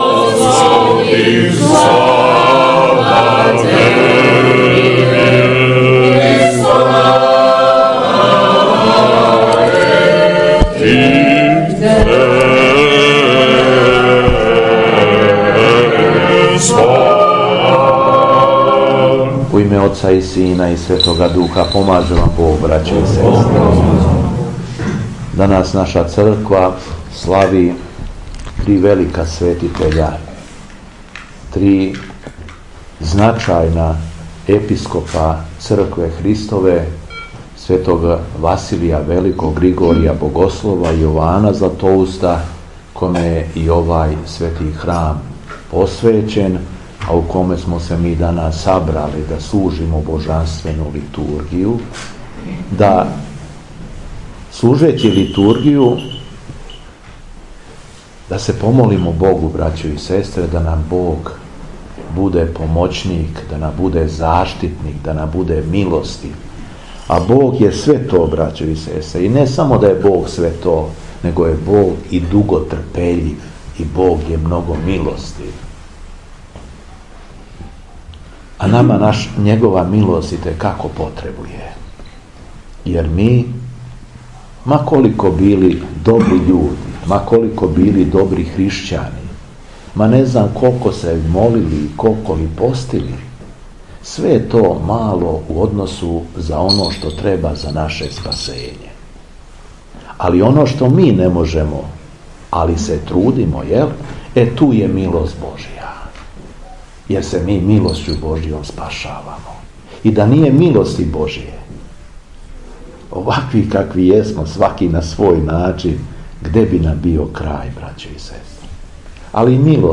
Беседа Епископа шумадијског Г. Јована
Његово Преосвештенство Епископ шумадијски Господин Јован на дан када славимо Света Три Јерарха, 12. фебруара 2020. године, служио је Божанствену Литургију у цркви Света Три Јерарха у Тополи.